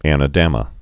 (ănə-dămə)